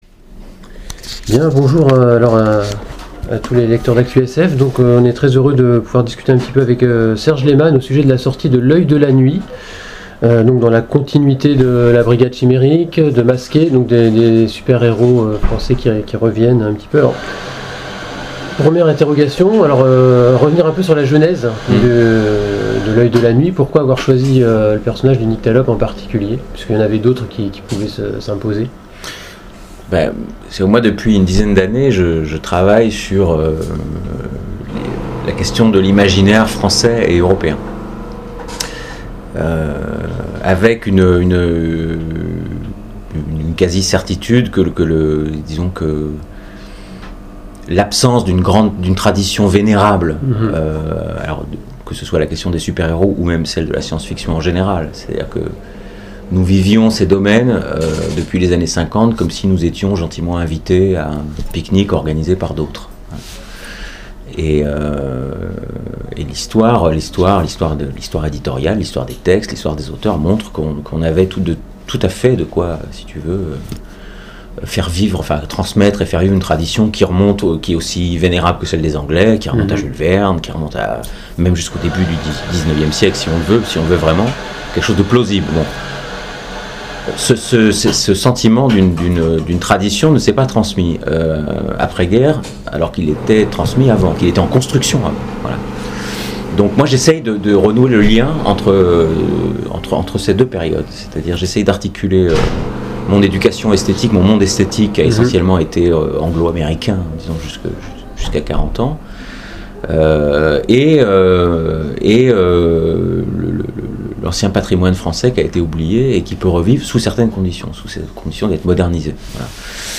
Interview de Serge Lehman pour l'Œil de la nuit